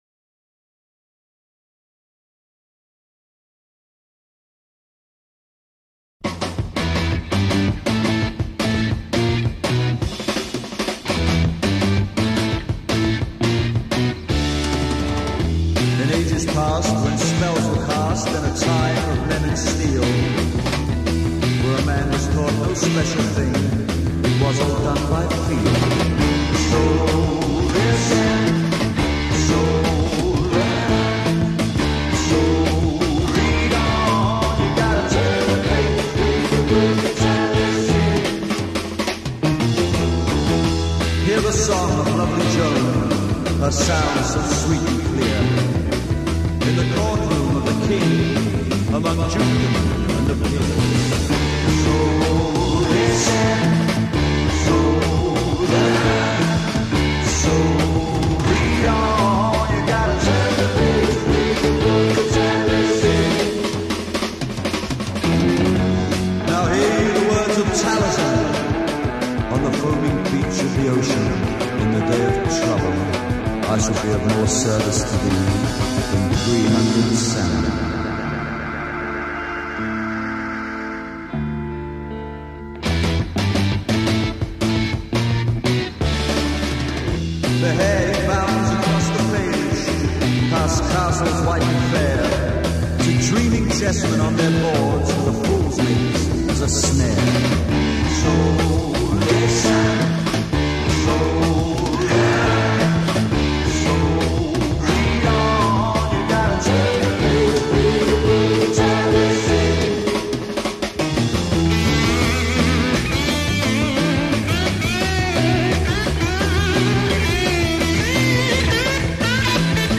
Classic Rock, Psychedelic Rock